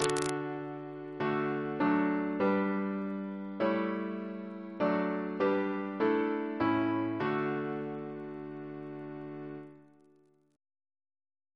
Single chant in D Composer: Donald B. Eperson (1904-2001) Reference psalters: ACB: 179